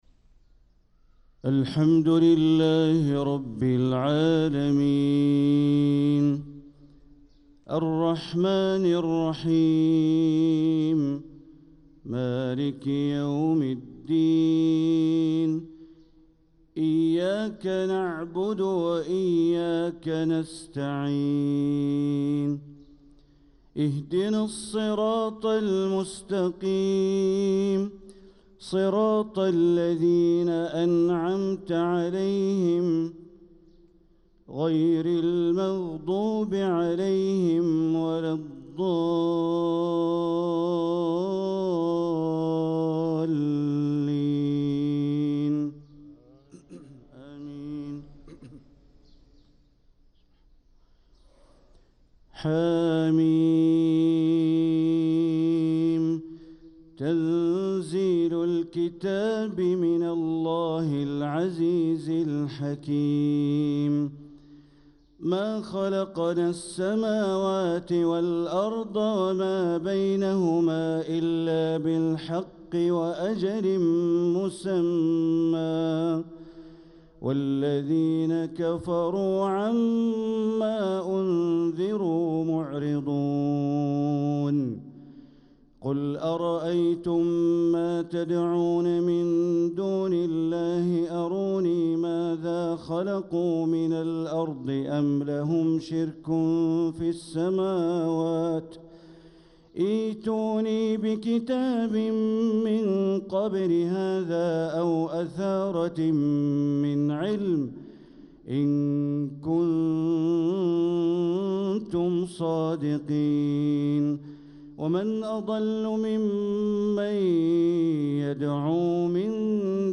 صلاة الفجر للقارئ بندر بليلة 18 رجب 1446 هـ